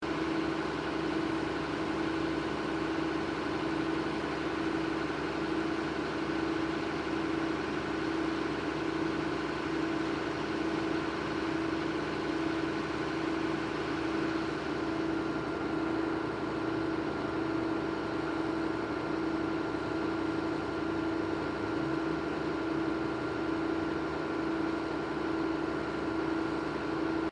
Machine
描述：snowboard waxer
标签： machine
声道立体声